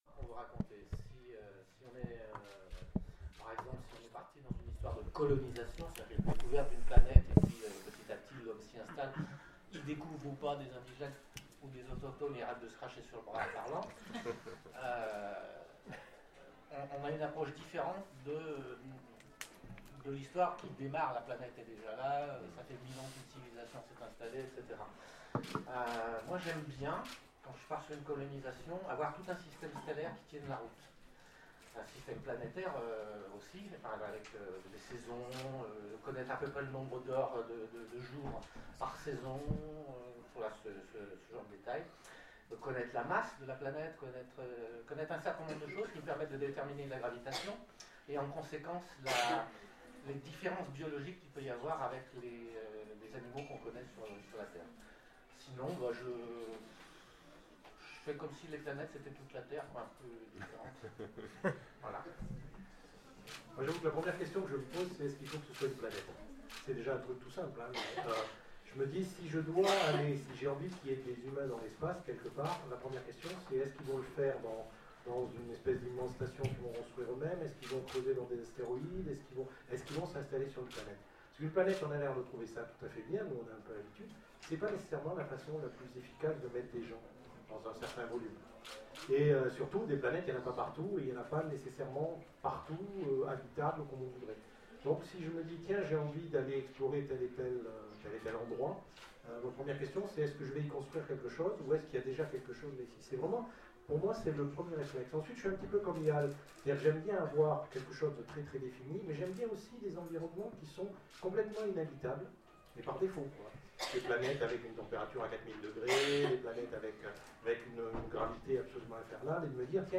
Les Oniriques 2015 : Table ronde Planétologie, comment naissent les planètes ?
Télécharger le MP3 à lire aussi Laurent Genefort Jean-Claude Dunyach Pierre Bordage Yal Ayerdhal Genres / Mots-clés Espace Conférence Partager cet article